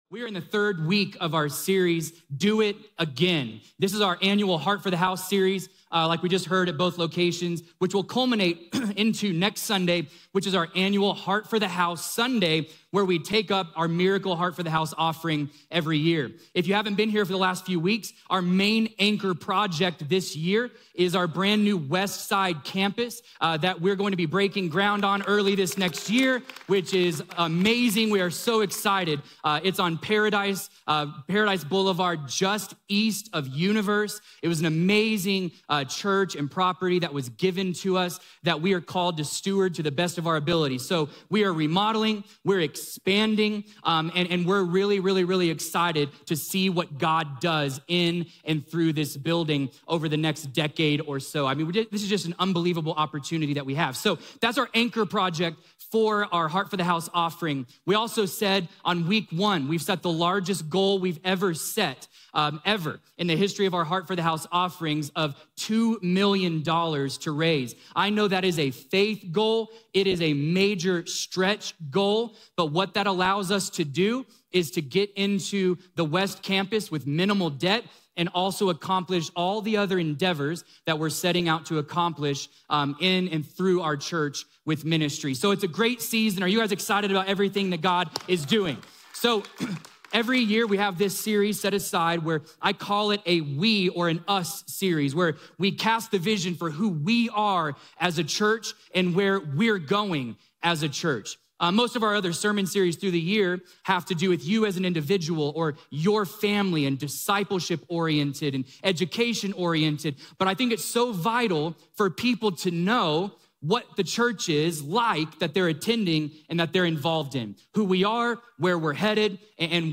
Subscribe to the Citizen Church Podcast and automatically receive our weekly sermons.